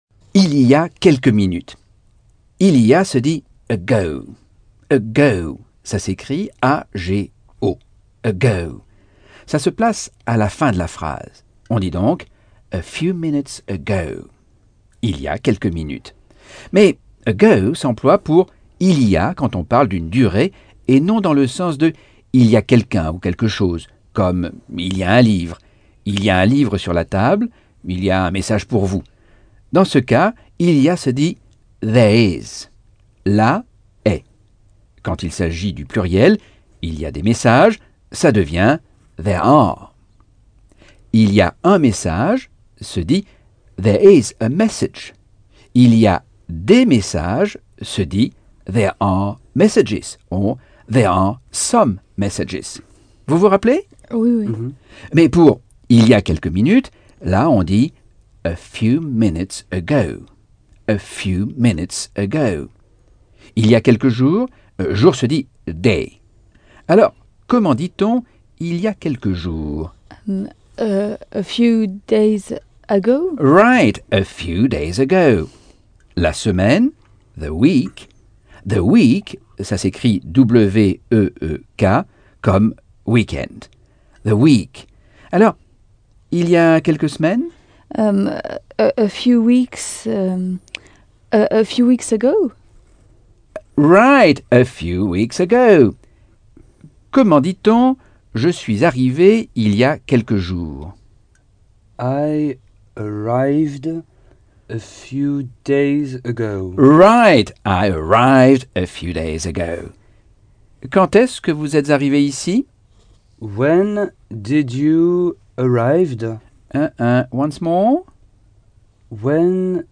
Leçon 2 - Cours audio Anglais par Michel Thomas - Chapitre 6